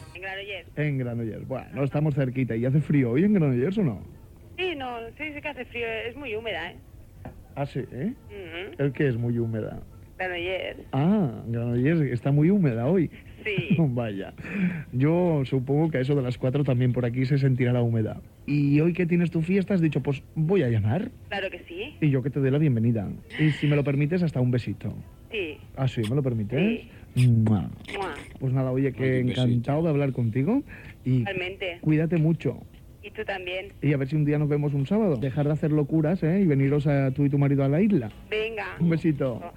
Trucada d'una oïdora Gènere radiofònic Participació